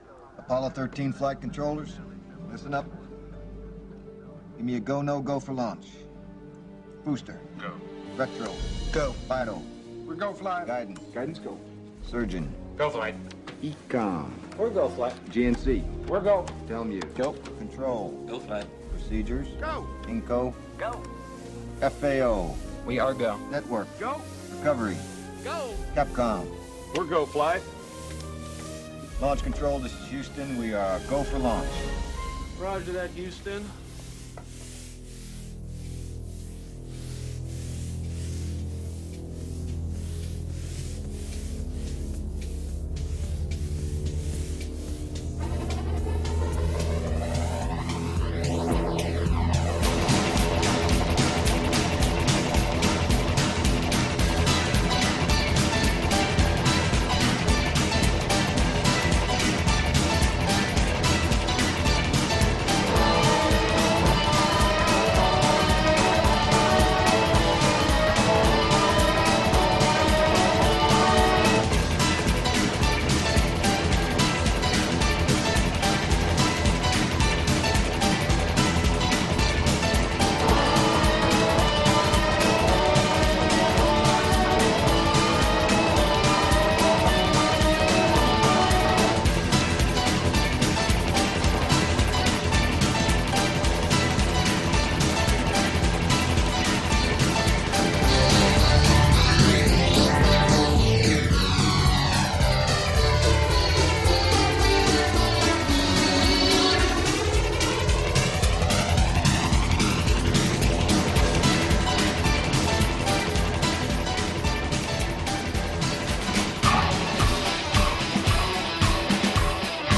The Vol. 1 Podcast is REAL ROCK from 1979 until NOW.